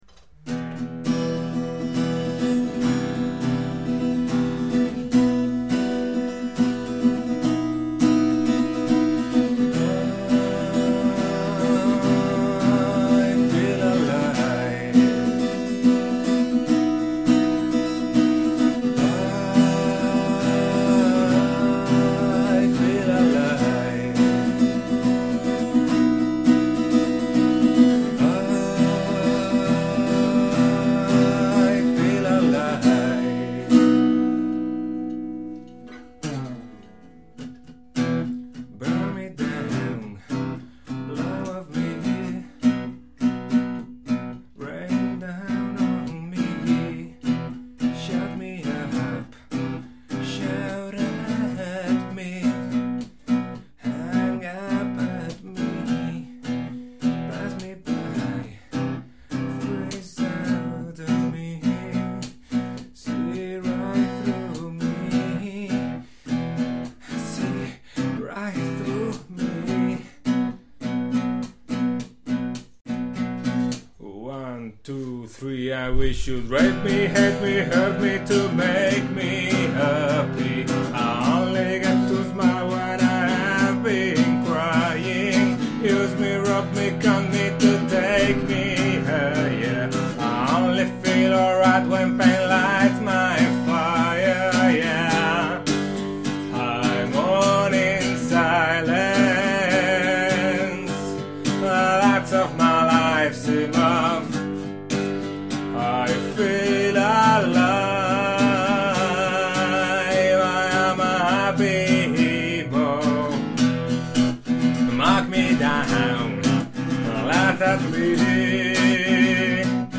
Our first full track in English. It was never properly recorded (it was intended for electric guitar + bass), but a couple of early acoustic demos have survived, one in audio only, the other one in a rehearsal video.
Happy-Emo-live-acoustic-2.mp3